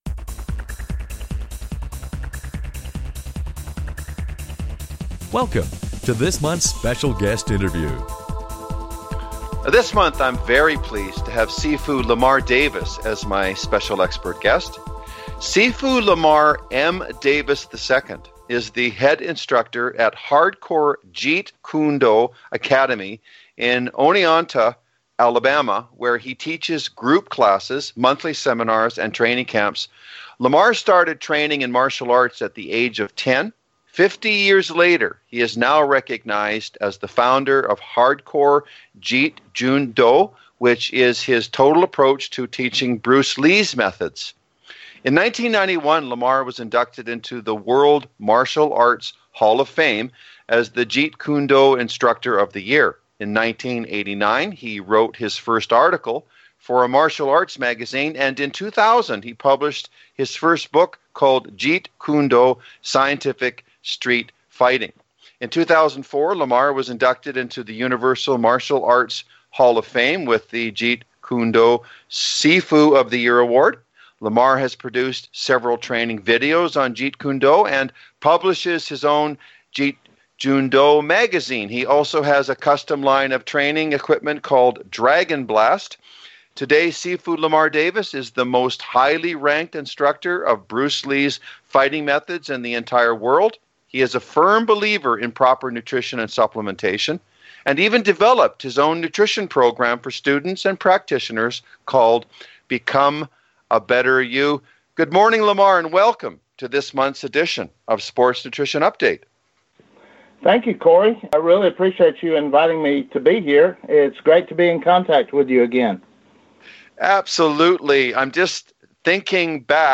Special Guest Interview